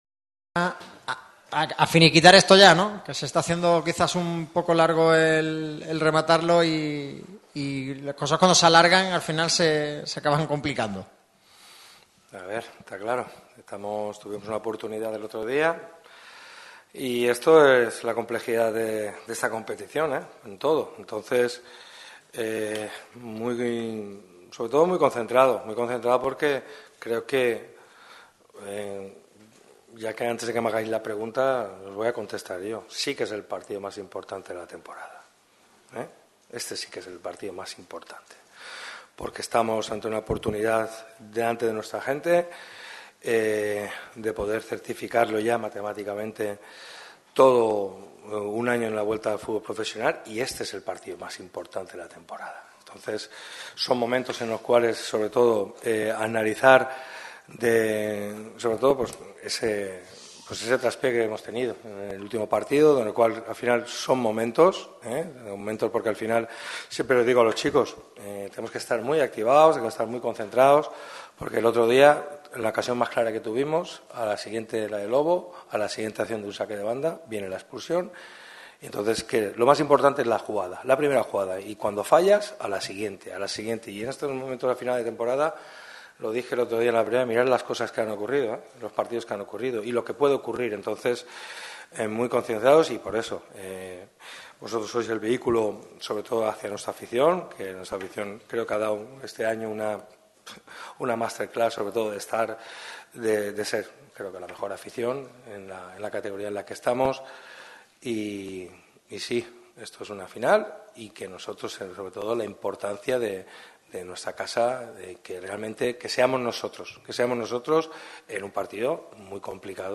El entrenador del Málaga CF ha comparecido ante los medios en la previa del duelo que enfrentará a los boquerones contra el Sporting de Gijón el próximo sábado a las 21:00 horas en La Rosaleda. El técnico pone en valor la importancia del partido y la necesidad de vencer para sellar la permanencia de una vez.